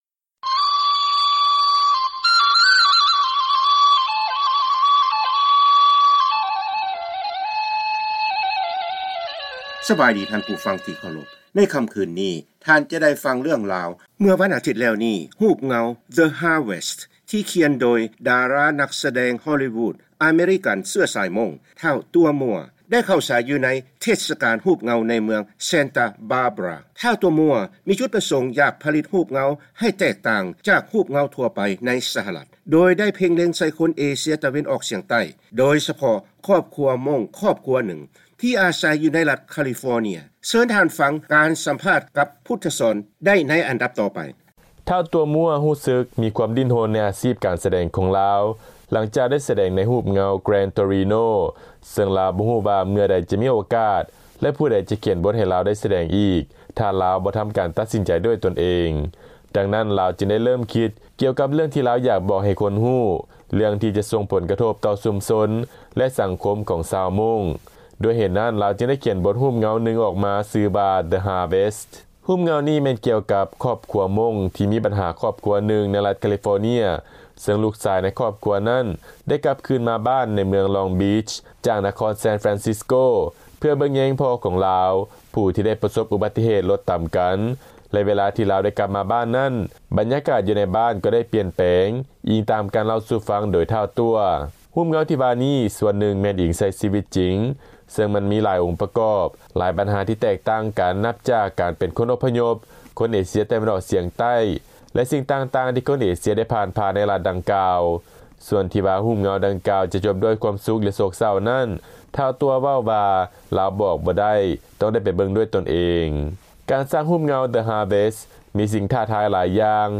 ວີໂອເອ ສຳພາດ